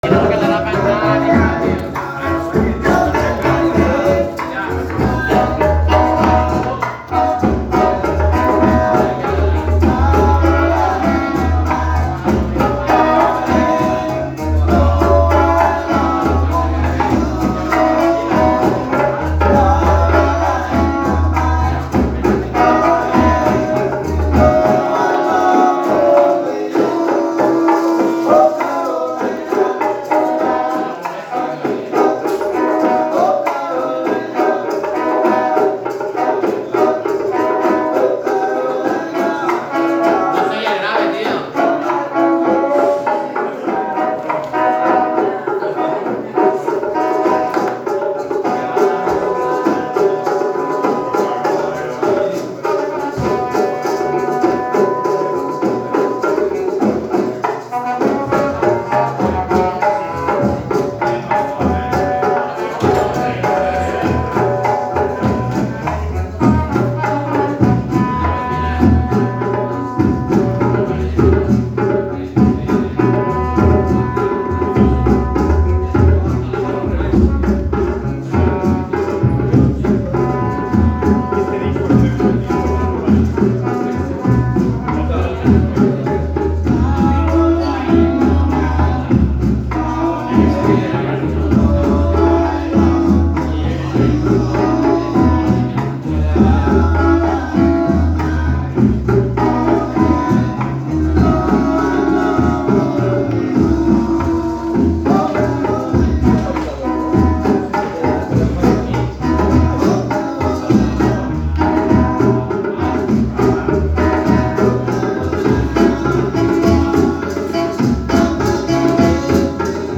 Warm up Dub Jam Session
"Los Trecers" Starts At 17h with roots & Culture selection Than 3 hs of live jam.
analog Fx